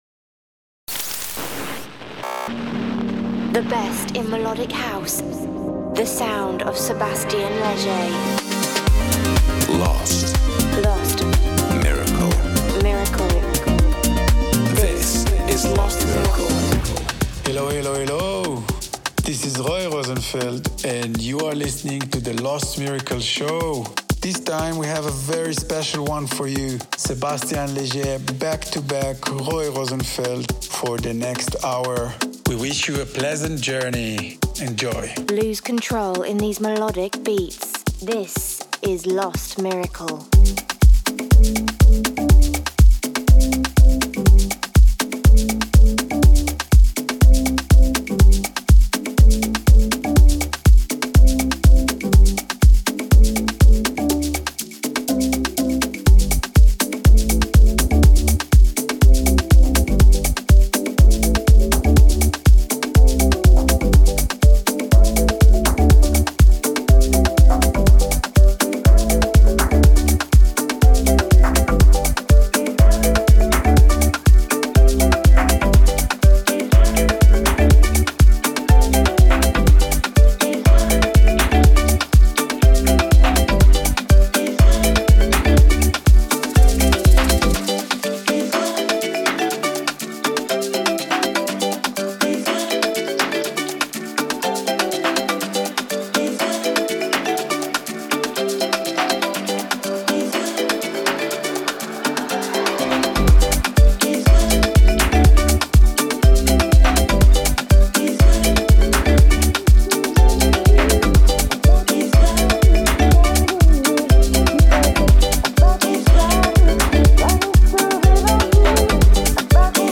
the monthly radio show